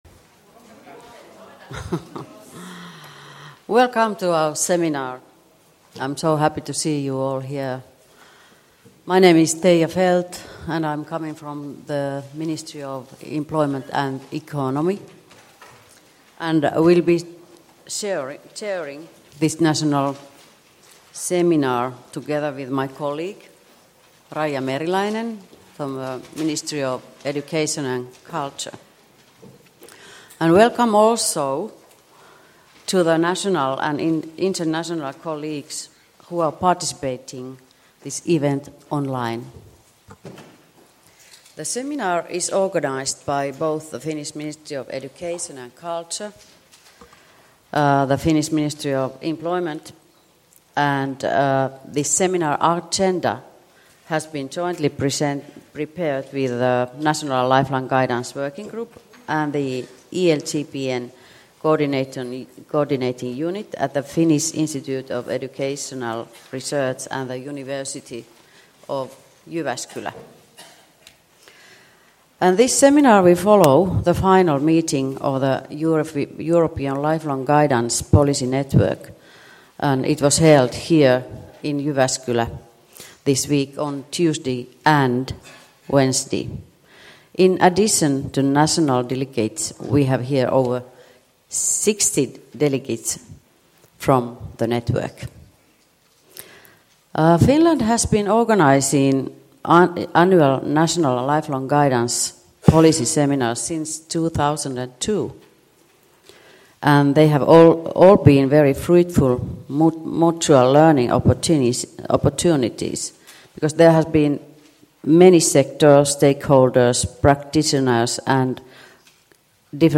“Ohjaamo” One-Stop Guidance Centers: Developing Policy and Practice for Co-careering - National Lifelong Guidance Policy Seminar 26.11.2015 Jyväskylä.